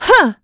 Valance-Vox_Attack3.wav